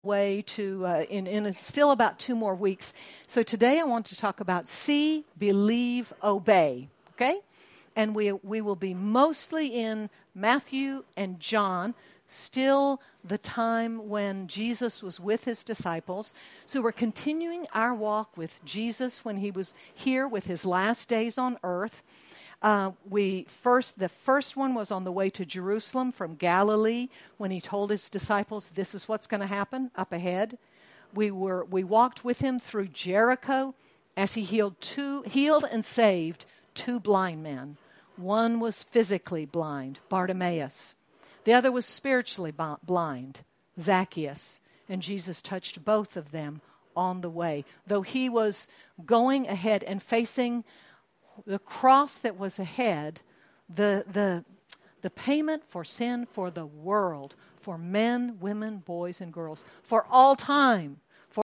Apr 21, 2024 See, Believe, Obey MP3 SUBSCRIBE on iTunes(Podcast) Notes Discussion In this message we continue looking at the appearances and encounters of Jesus with his followers after His resurrection. As with them, Jesus wants us to see, to believe, and obey. Sermon by